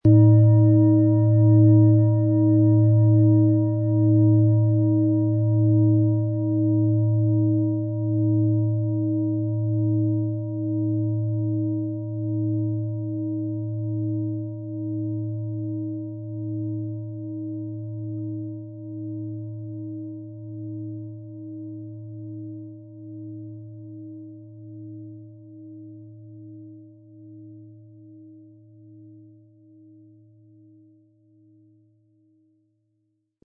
Es ist eine von Hand getriebene Klangschale, aus einer traditionellen Manufaktur.
• Höchster Ton: Saturn
Den passenden Klöppel erhalten Sie umsonst mitgeliefert, er lässt die Schale voll und wohltuend klingen.
PlanetentonWasserstoffgamma & Saturn (Höchster Ton)
MaterialBronze